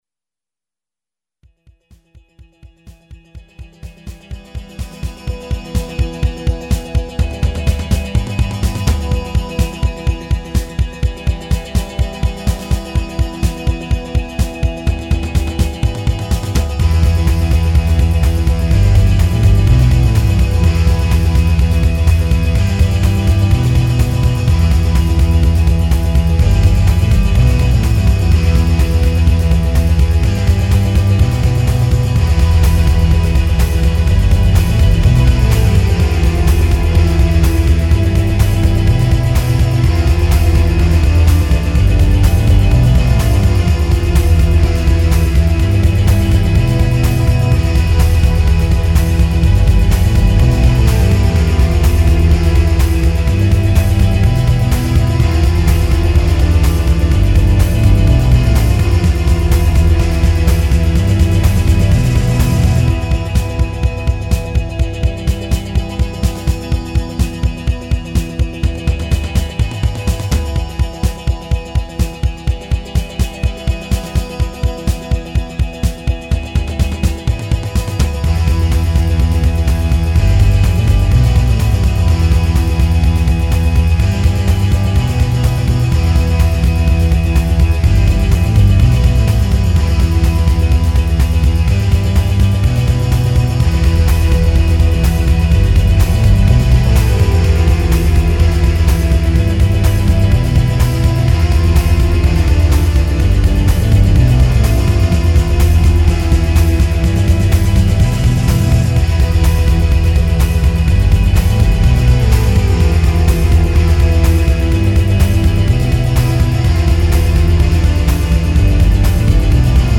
TekNoE Rock
'05 Remix EQ